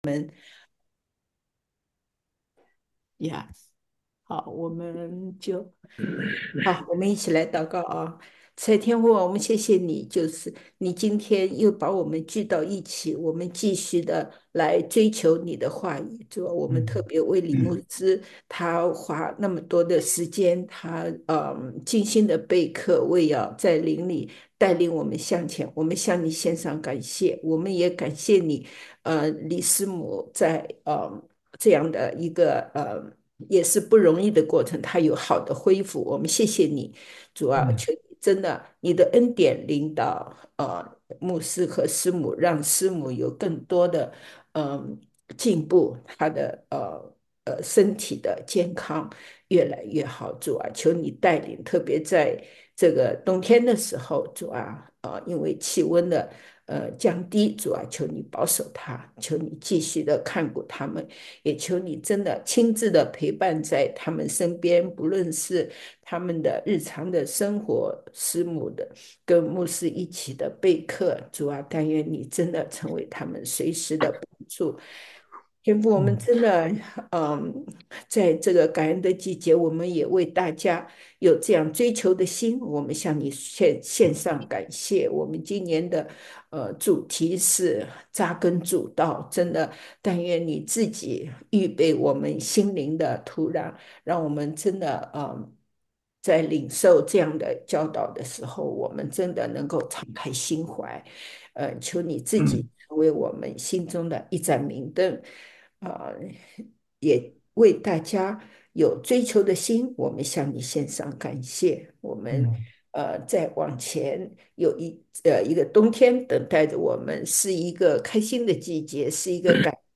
来自讲道系列 "進深查考：“小啟示錄”和《啟示錄》"